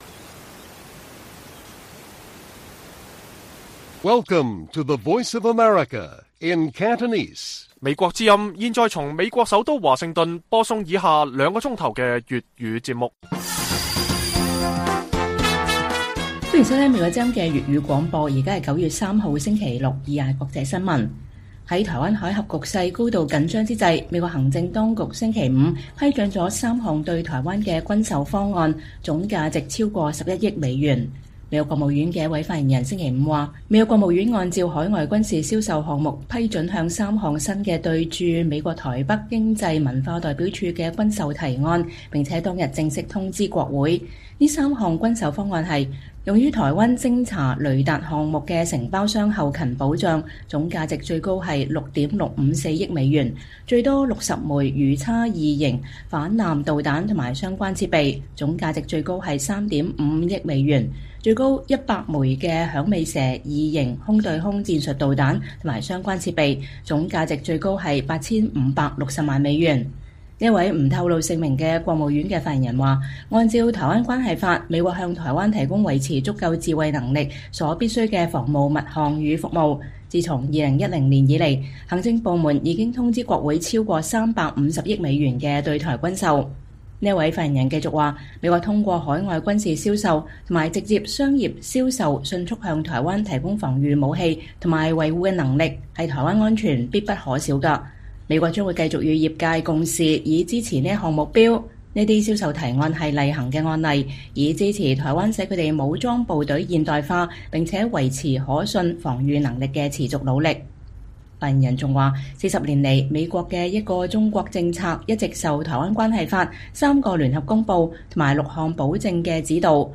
粵語新聞 晚上9-10點：美行政當局批准三項新對台軍售案，總價值逾11億美元